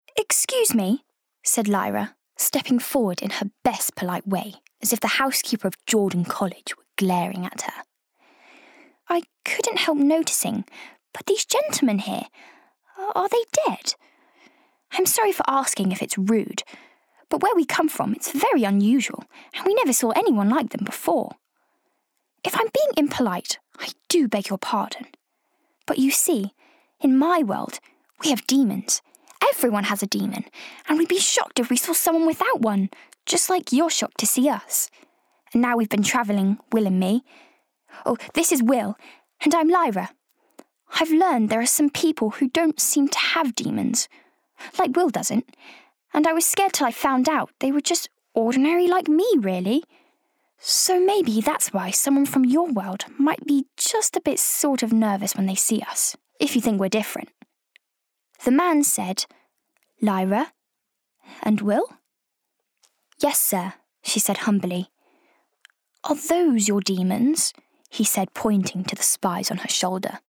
US/Neutral/Spanish Bright/Confident ← go back Related News 18 th June 2020 Loud & Clear’s Voice Talent sta... Commercial Animation Audio Books Foreign Pizza Hut – Lively Rhyme Time Town Amber Spyglass – Storytelling Percy Jackson – US accent Spanish Intro The Medium (Sadness)